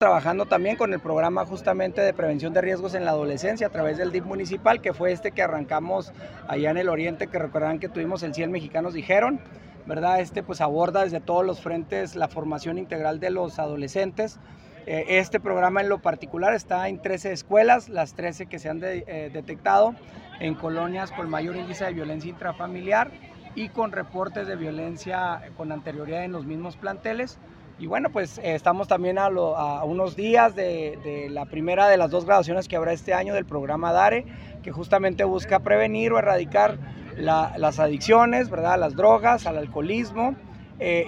AUDIO: PRESIDENTE MUNICIPAL MARCO ANTONIO BONILLA